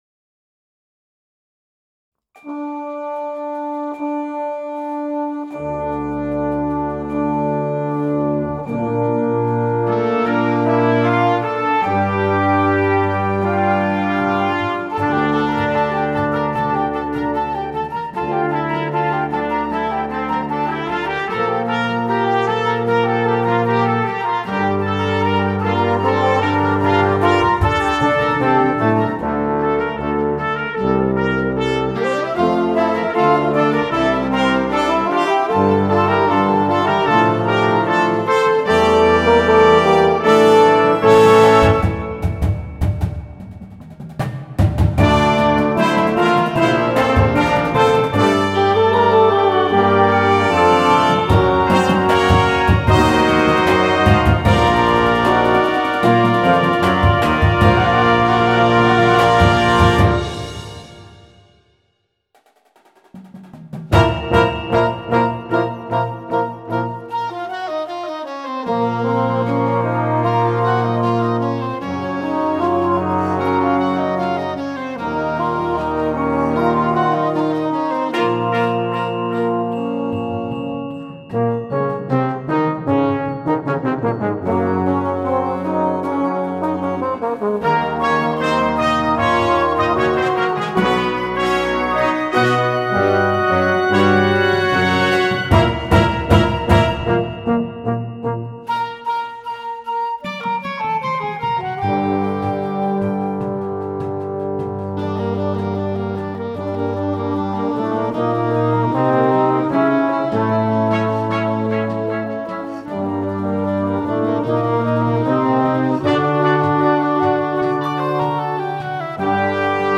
Full Show (short version) with Basic Percussion Only